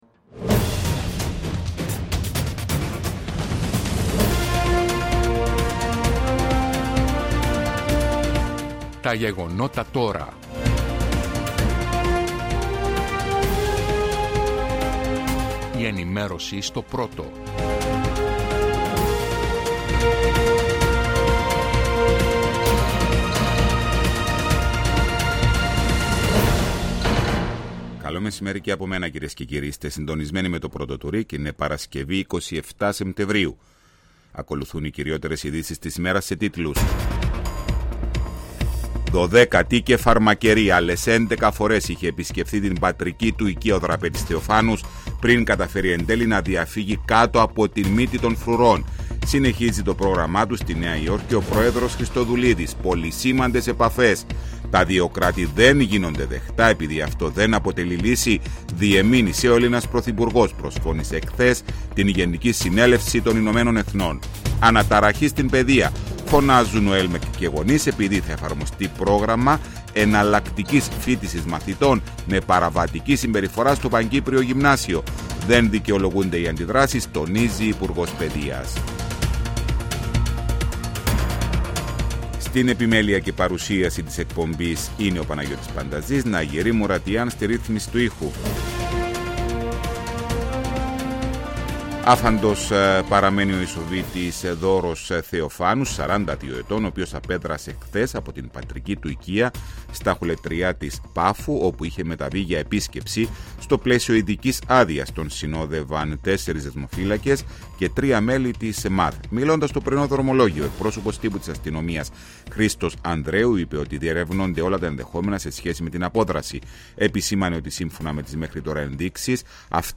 Η επικαιρότητα της ημέρας αναλυτικά με ρεπορτάζ, συνεντεύξεις και ανταποκρίσεις από Κύπρο και εξωτερικό.